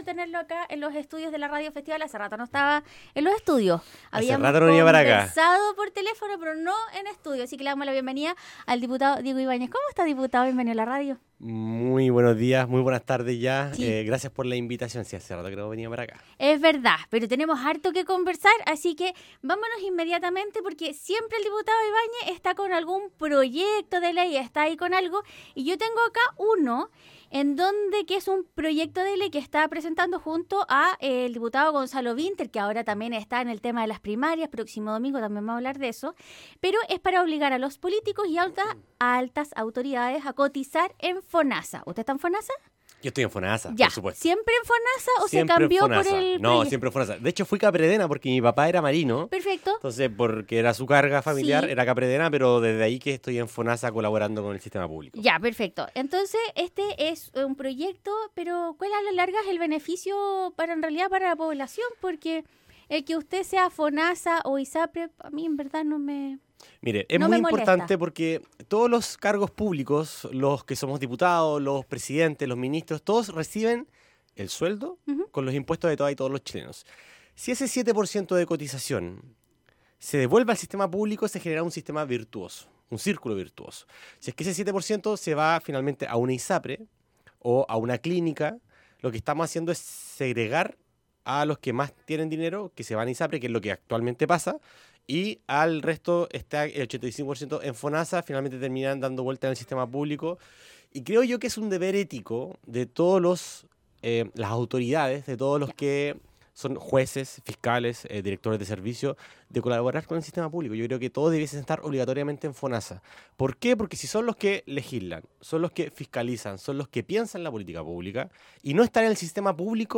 Conversando de actualidad junto al Diputado Diego Ibañez